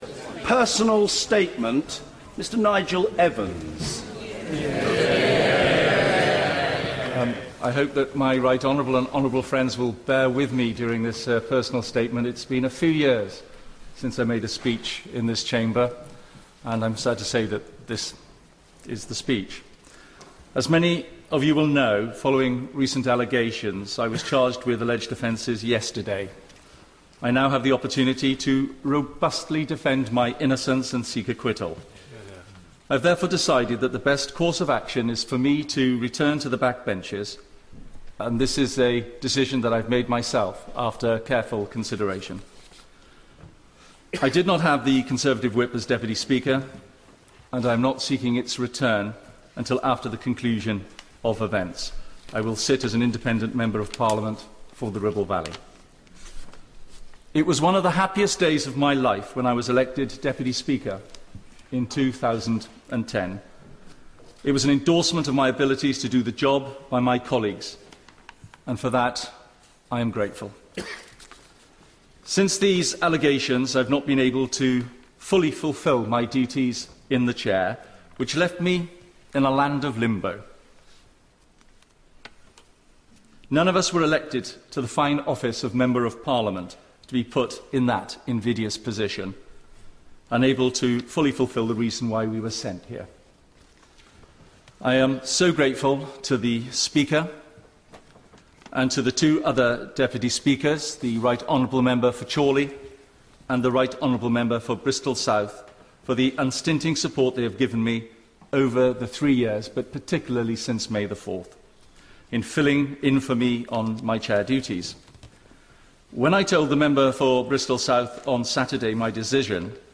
After being charged with sexual offences and resigning as Deputy Speak, Nigel Evans makes a statement in the House of Commons, 11 September 2013.